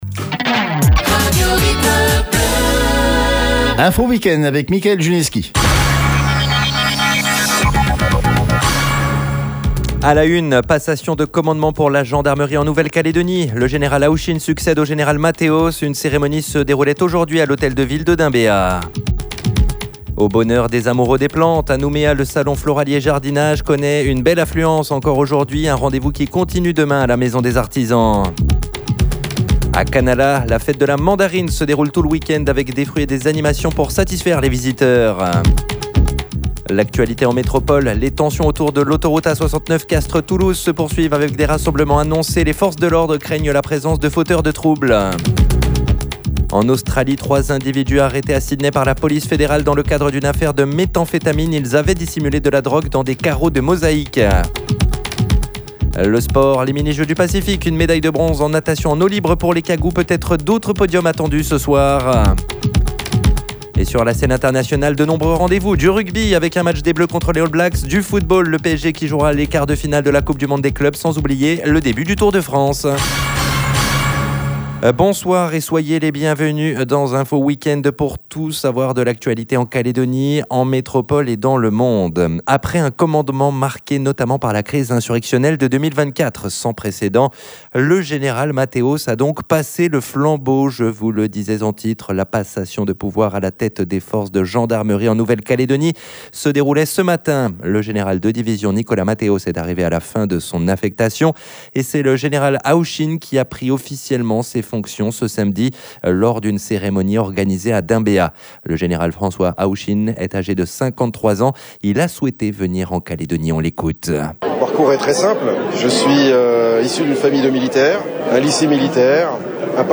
JOURNAL : INFO WEEK-END SAMEDI SOIR 05/07/25